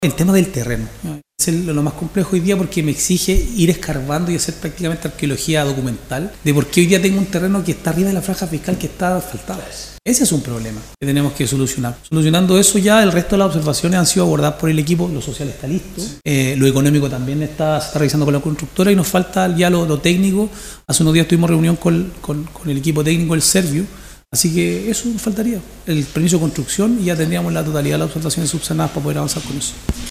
En el último Concejo comunal se conocieron los enormes avances que ha tenido en torno al tema una zona que también espera el aporte, con recursos, del Ministerio de Vivienda y Urbanismo (MINVU).
explicó en la última sesión del Concejo Municipal puconino